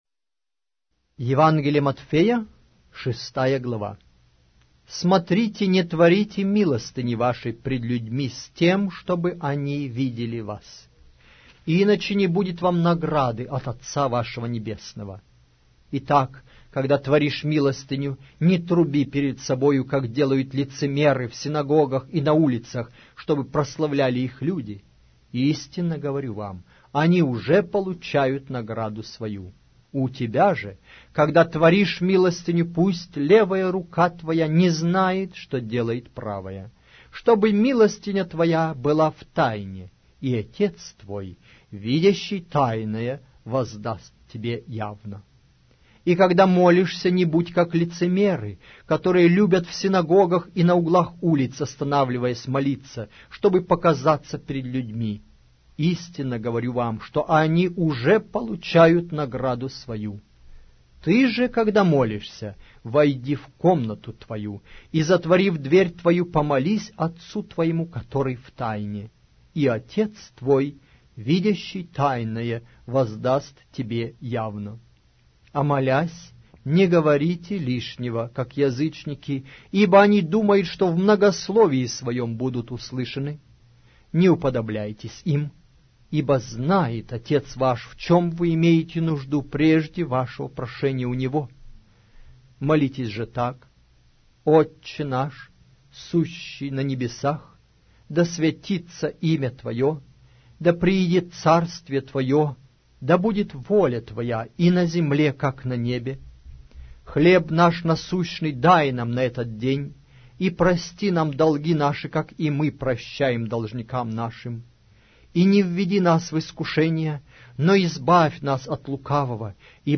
Аудиокнига: Евангелие от Матфея